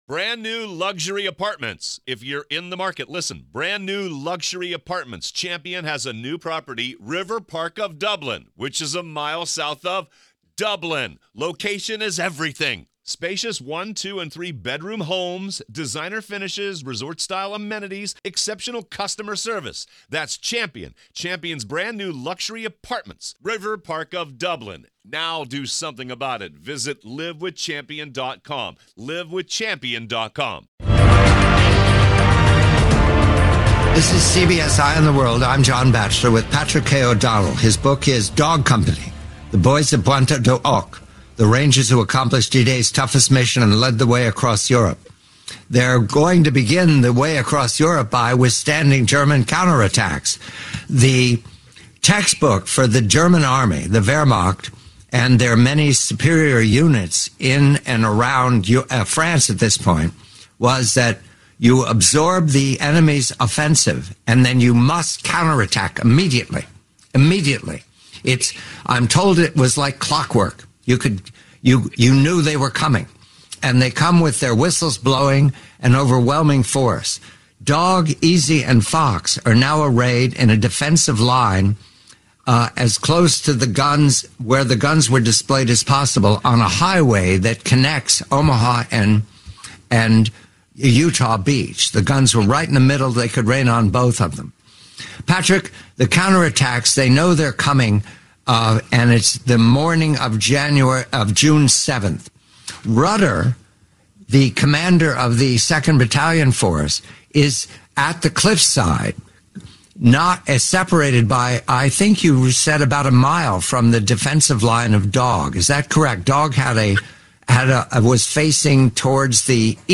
Blackstone Audio, Inc. Audible Audiobook – Unabridged